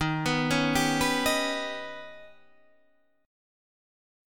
Eb+7 chord